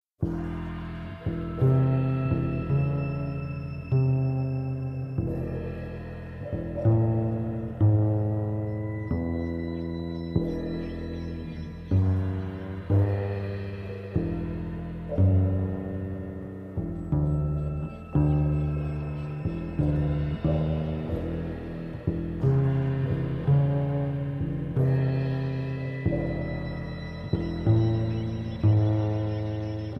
Guitar and Processed Guitar
Acoustic Bass
Drums and Percussion